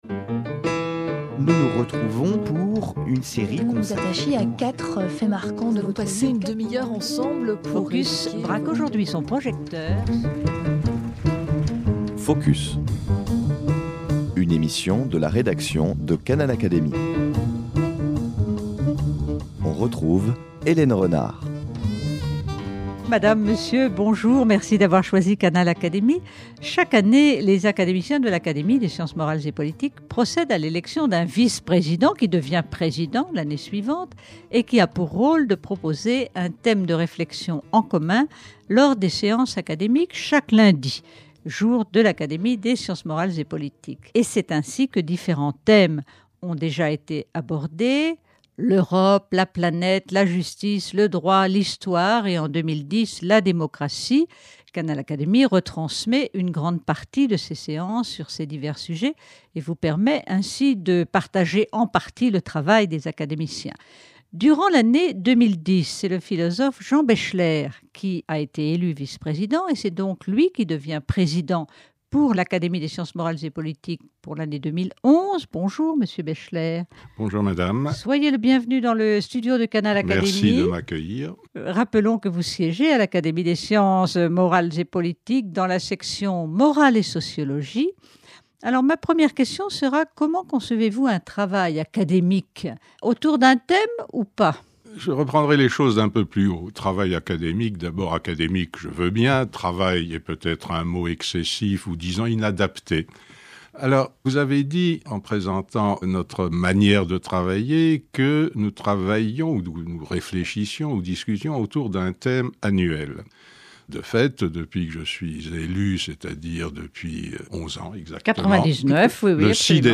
Jean Baechler, sociologue et philosophe, expose ici les raisons pour lesquelles il a choisi d’intituler "Entretiens de l’Académie" l’ensemble des communications qui seront proposées en 2011 devant les membres de l’Académie des sciences morales et politiques.